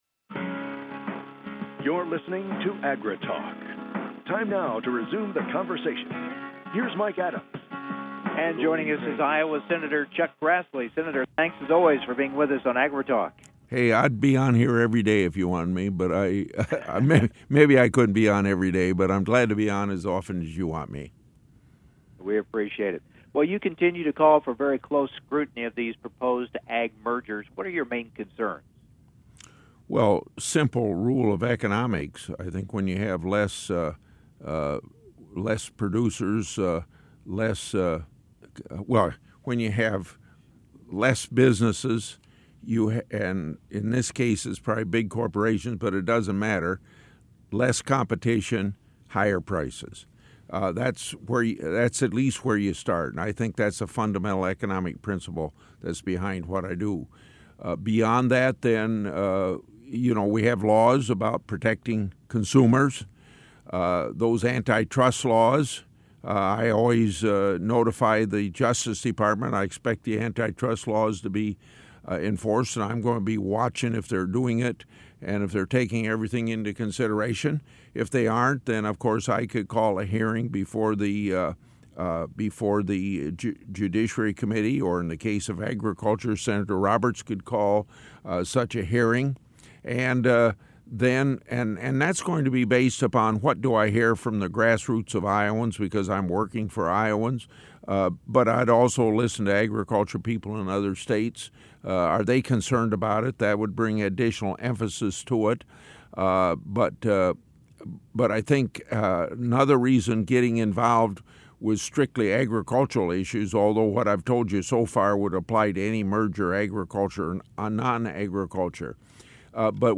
Public Affairs Program, 6-16-16, AgriTalk.mp3